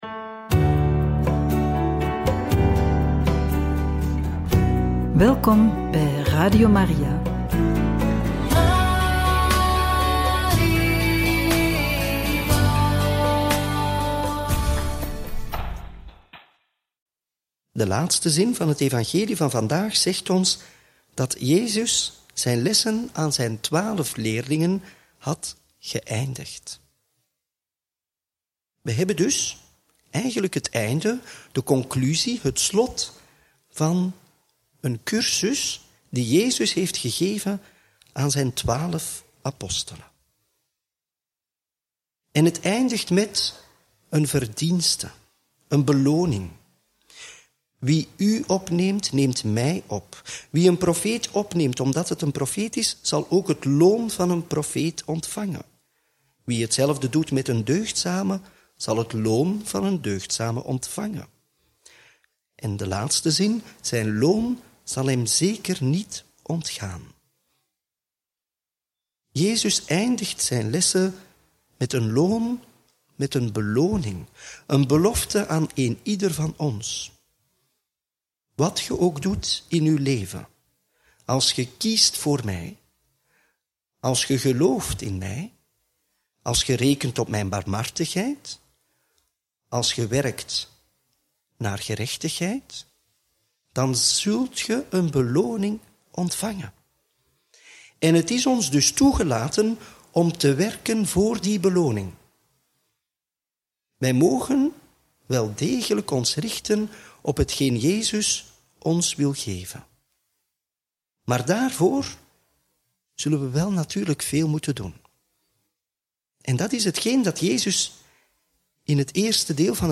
Homilie bij het Evangelie van maandag 14 juli 2025 – Mt 10, 34-11, 1